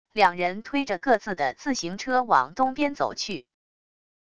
两人推着各自的自行车往东边走去wav音频生成系统WAV Audio Player